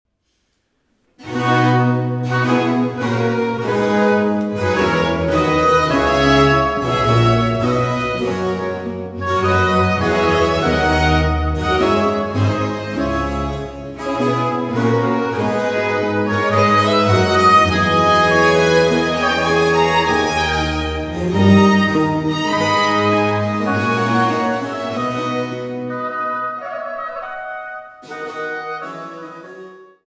Instrumetalmusik für Hof, Kirche, Oper und Kammer
für zwei Oboen, Fagott, Steicher und Basso continuo